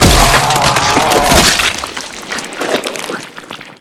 spacewormdie.ogg